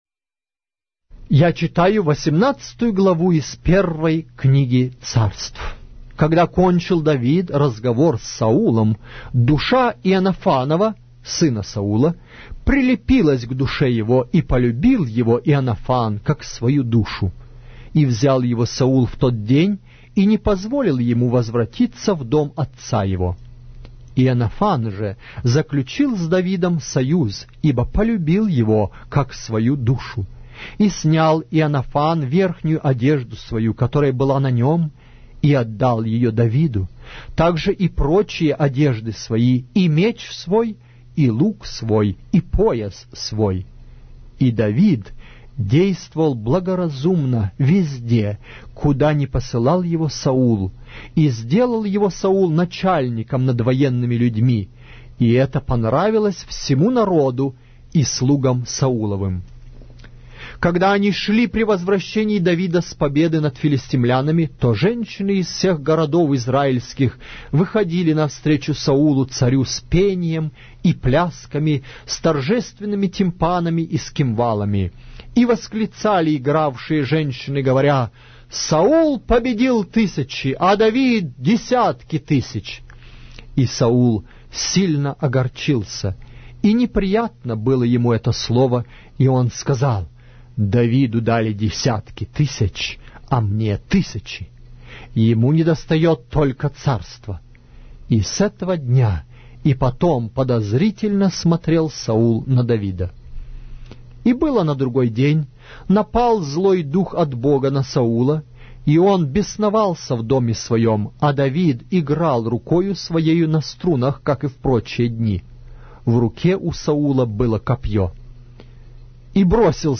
Глава русской Библии с аудио повествования - 1 Samuel, chapter 18 of the Holy Bible in Russian language